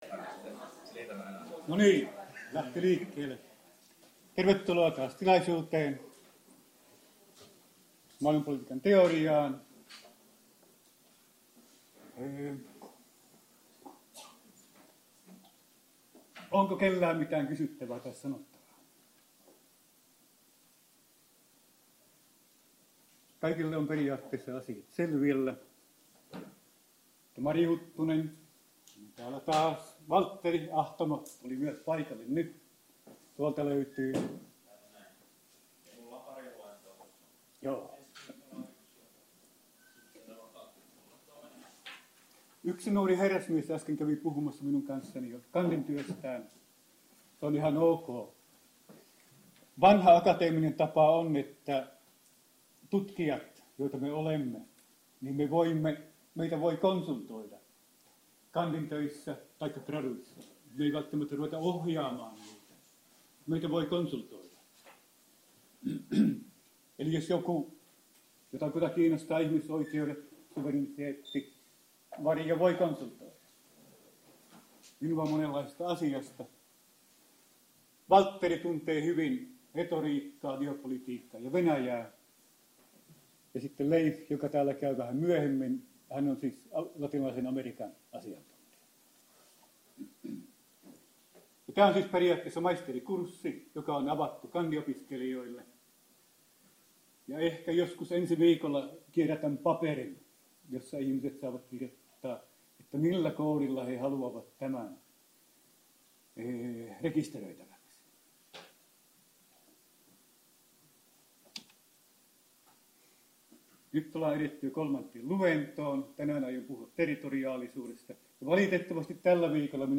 POLS3017- Luento 3 35e8bd502c164b45a590824a9f808211